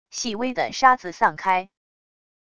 细微的沙子散开wav音频